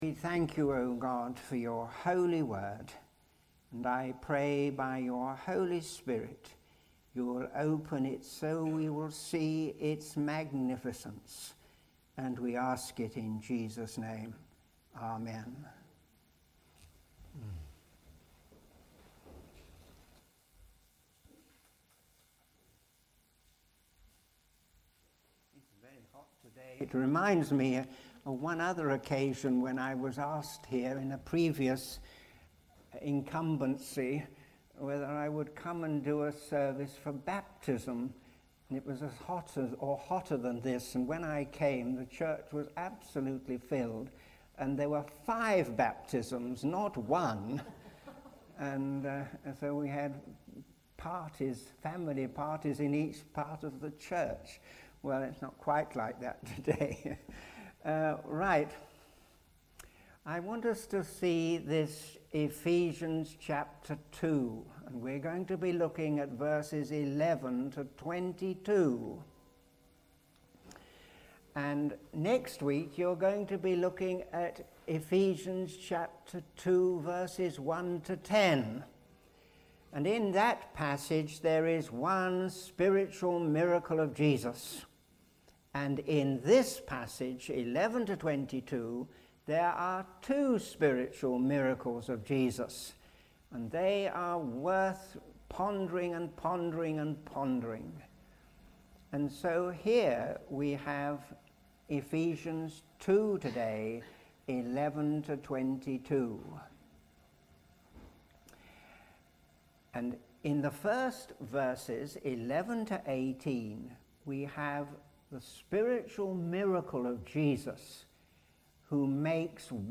Sermon
(volume problem until the 2 minute point; OK after that). Amazing Grace – Ephesians 2:1-10